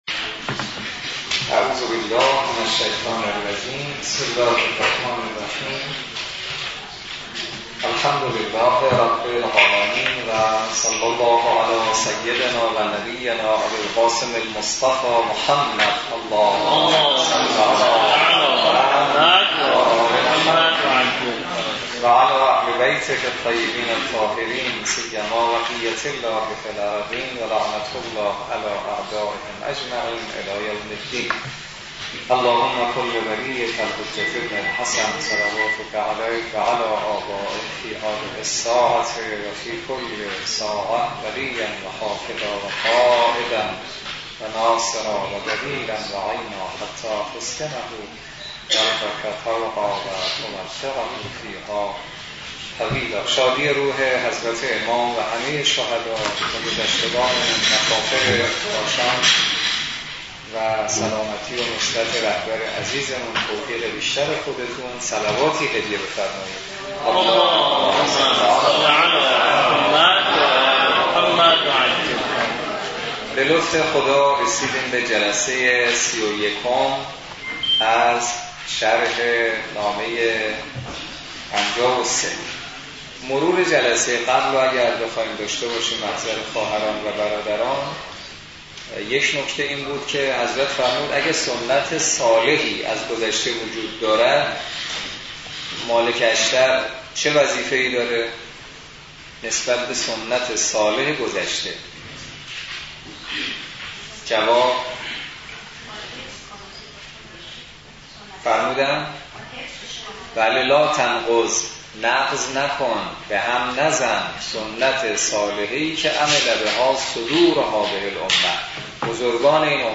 برگزاری سی و یکمین جلسه تفسیر نامه ۵۳ نهج البلاغه توسط نماینده محترم ولی فقیه و در دانشگاه کاشان - نهاد نمایندگی مقام معظم رهبری در دانشگاه کاشان
سخنرانی
سی و یکمین جلسه تفسیر نامه ۵۳ نهج البلاغه توسط حجت‌الاسلام والمسلمین حسینی نماینده محترم ولی فقیه و امام جمعه کاشان در دانشگاه کاشان برگزار گردید.